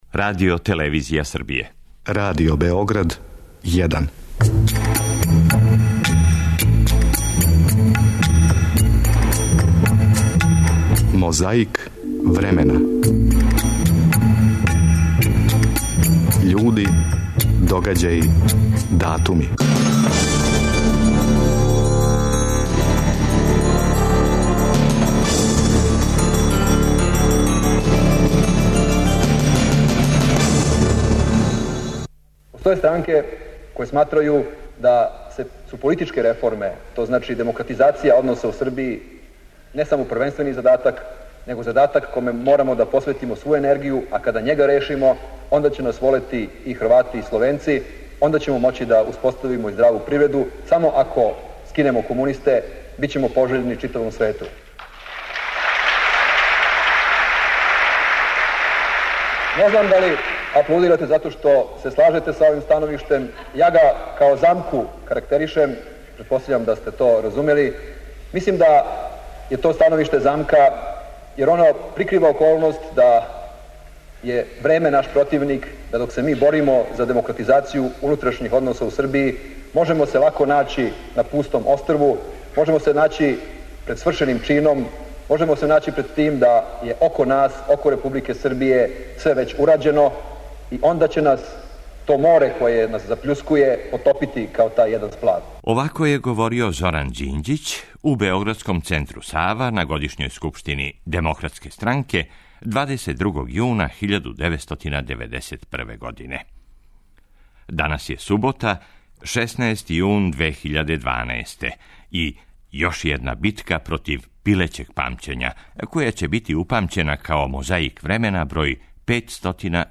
У Мозаику се, у складу са датумом на календару, окрећемо догађајима из скорије и не тако скоре прошлости, који су се збили средином јуна. Случајно или не, архива Радио Београда, када је реч о јунским догађајима, углавном располаже записима који се односе на војску и оржане сукобе.
Ту је, пре свега, наша политичка сцена деведесетих: У Центру Сава 22. јуна 1991. одржана је годишња скупштина Демократске странке на којој је говорио Зоран Ђинђић.